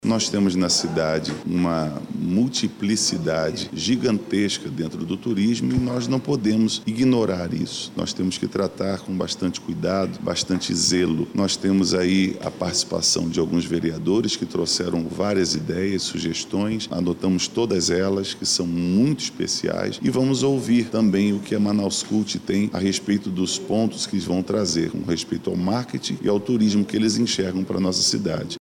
Ainda segundo o parlamentar, o setor de Turismo, em Manaus, possui diversas potencialidades que precisam ser impulsionadas.